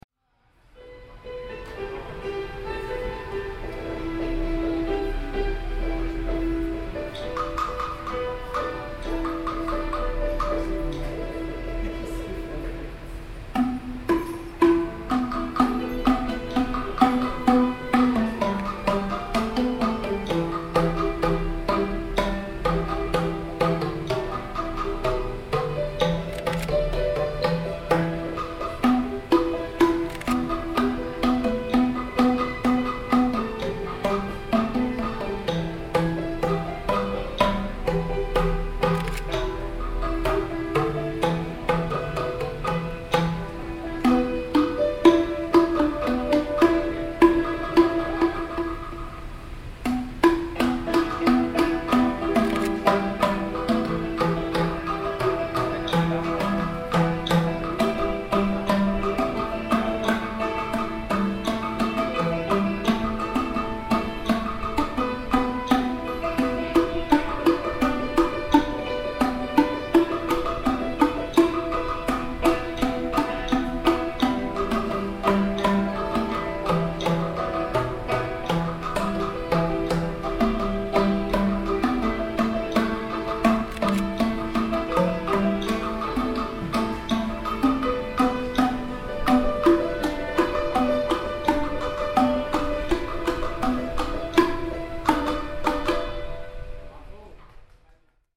In one of it’s pavilions … traditional folk instrument performances are presented daily.
Bamboo ‘Clapophone’ (K’longput) – 1.6mins
This beautifully mellow sounding instrument is played by gently clapping in front of the open-ended bamboo tubes.
hanoi-temple-of-literature-folk-music-performance-clapophone-3rdjune11.mp3